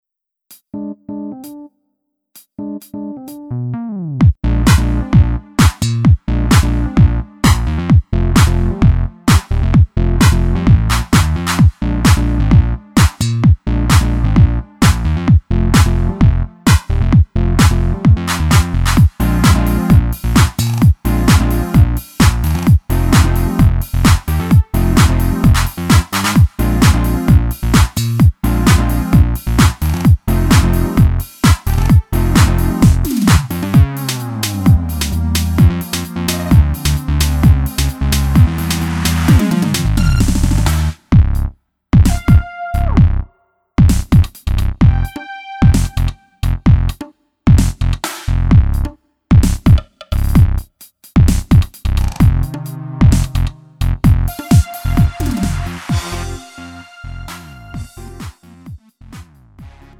음정 원키 3:13
장르 구분 Lite MR